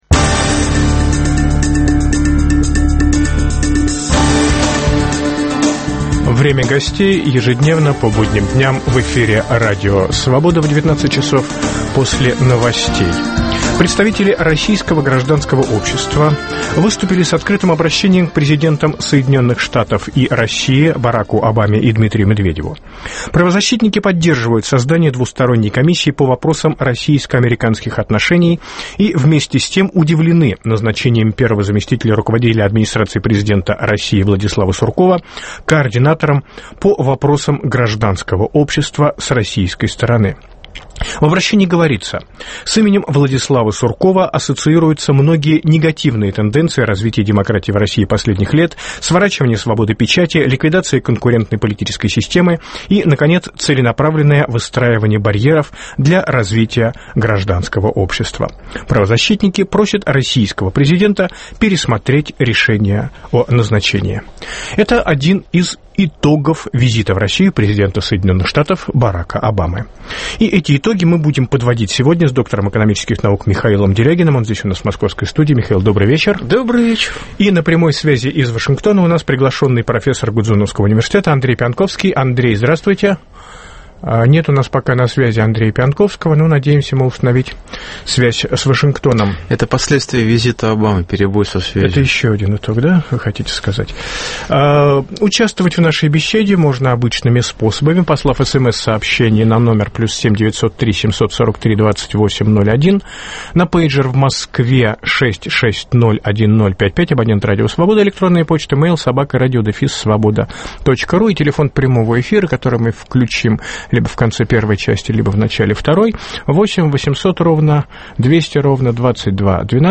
Итоги визита президента США Барака Обамы в Россию в программе подводят – из Вашингтона приглашенный профессор Гудзоновского университета Андрей Пионтковский, из Москвы – доктор экономических наук Михаил Делягин.